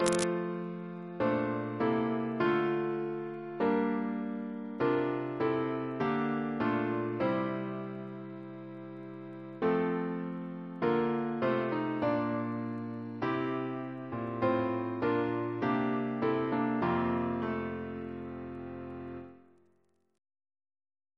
Double chant in F Composer: Walter Biery (b.1958)